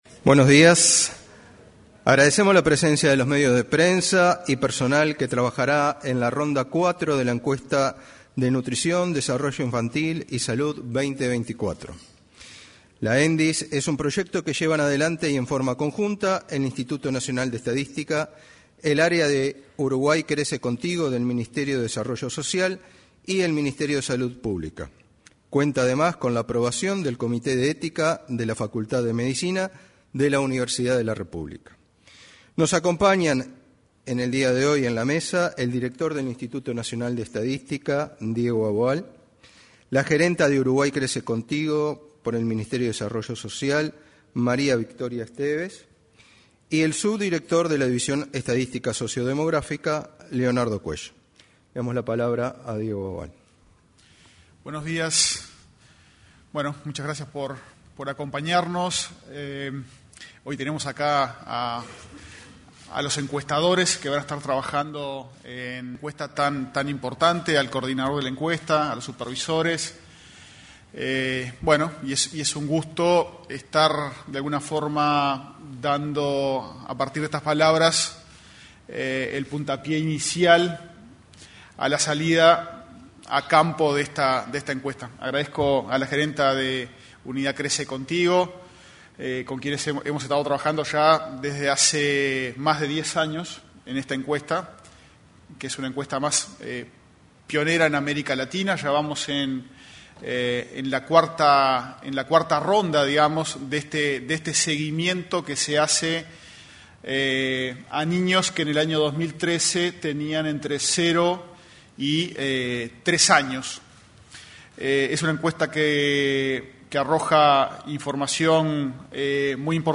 En el lanzamiento de la Encuesta de Nutrición, Desarrollo Infantil y Salud 2024, realizado este miércoles 31, se expresaron el director técnico del